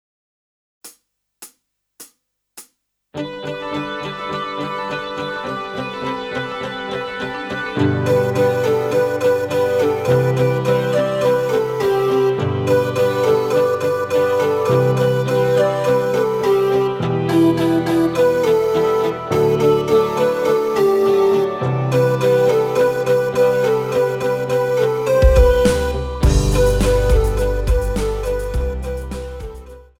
Žánr: Pop
BPM: 104
Key: G
MP3 ukázka